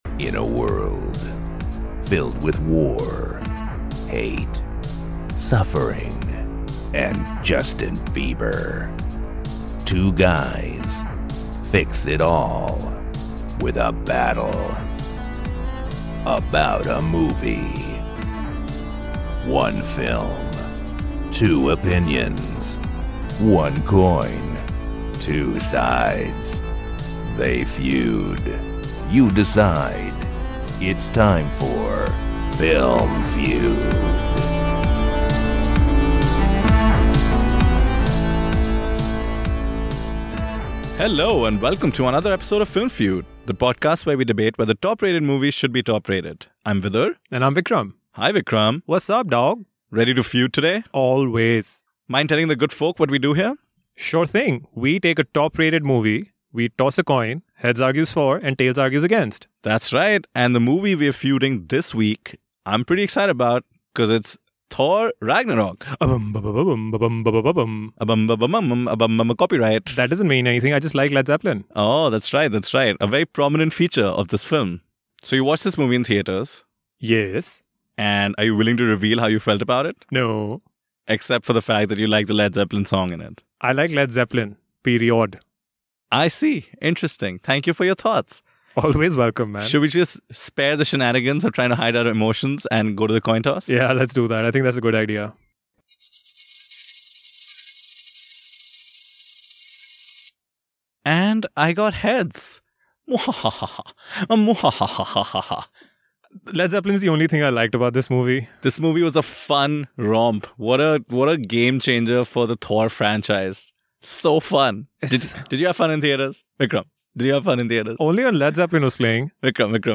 Get ready for Korg impressions, Hela puns and Led Zeppelin screams.